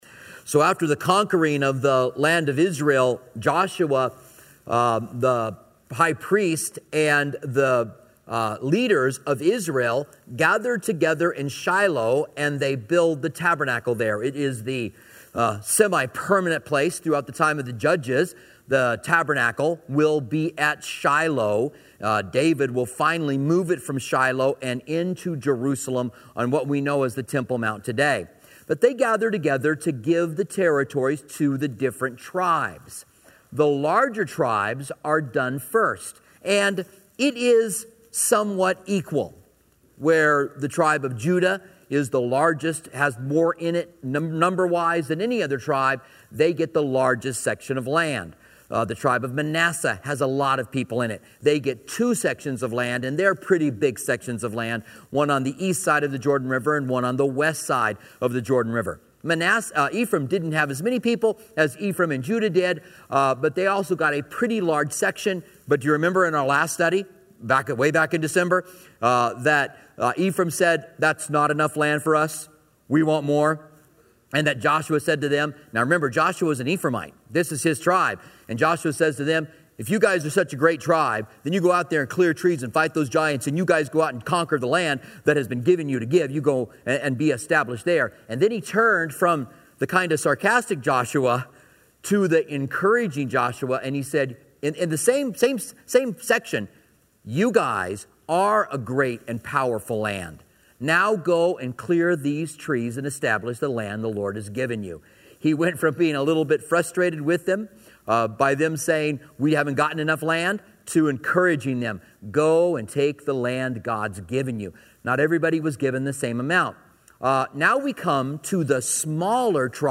Commentary on Joshua